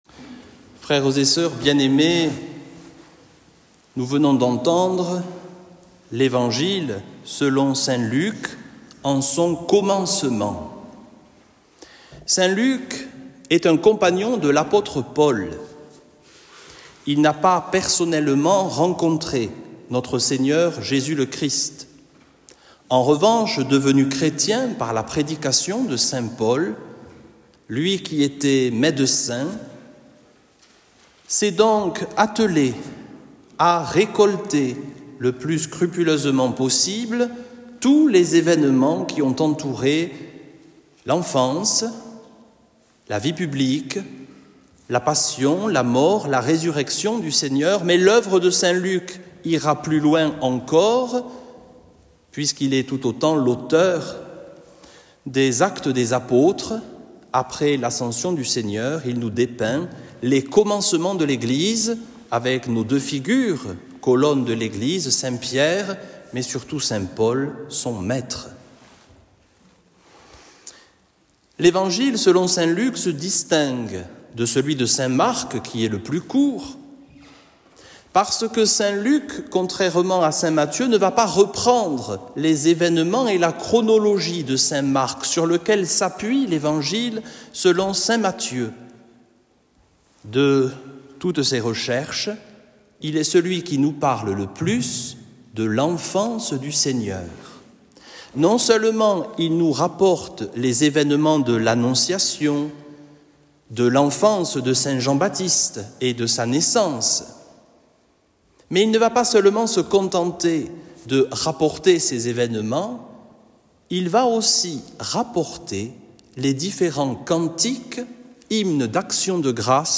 Homélie au IIIe Dimanche du Temps Ordinaire - Vox in deserto
homelie-au-iiie-dimanche-du-temps-ordinaire-2.mp3